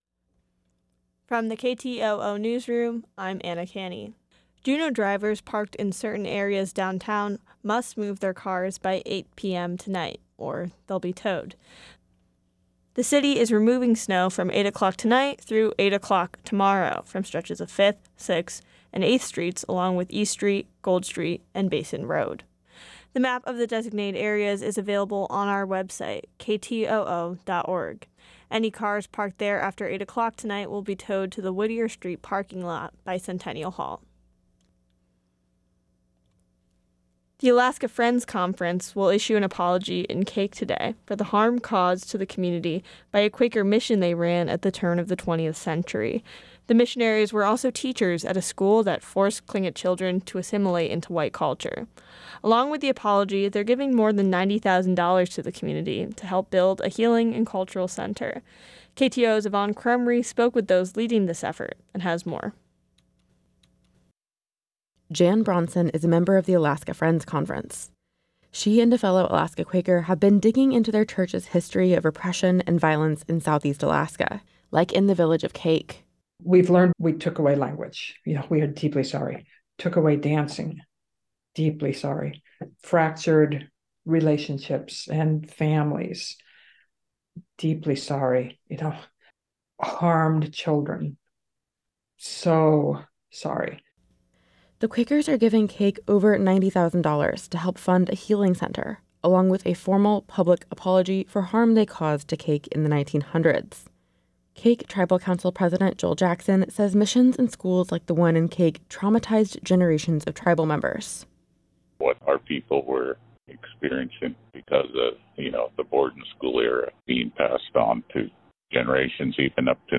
Newscast – Friday, Jan. 19, 2024